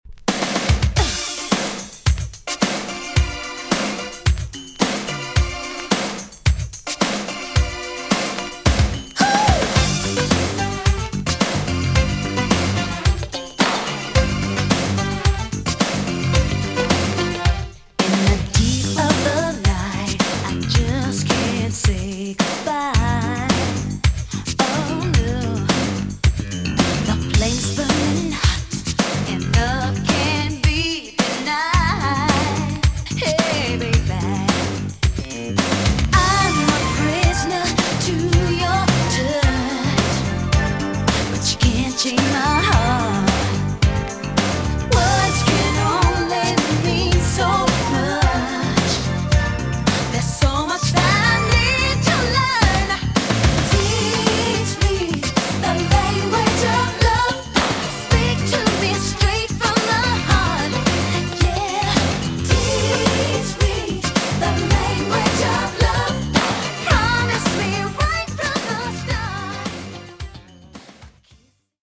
TOP >JAMAICAN SOUL & etc
EX- 音はキレイです。